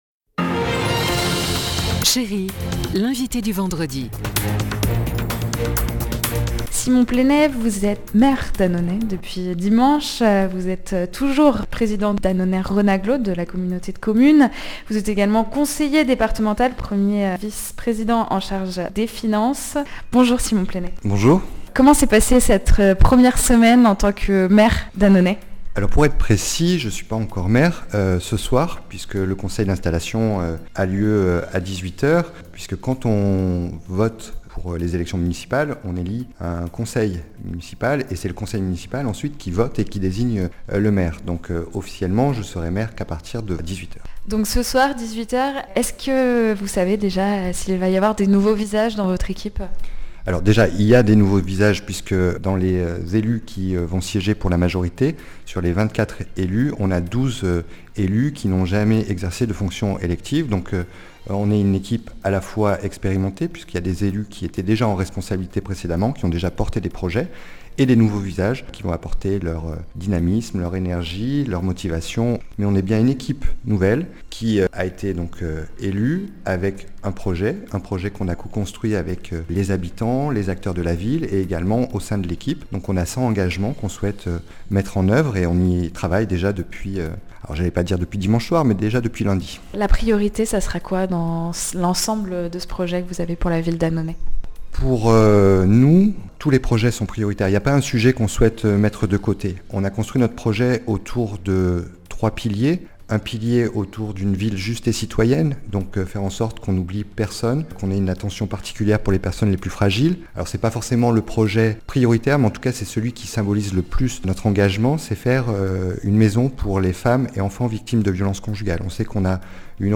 Annonay : le nouveau maire était notre invité
Simon Plénet est le nouveau maire d’Annonay. Et hier, il était notre invité à Chérie FM Annonay et Vallée du Rhône.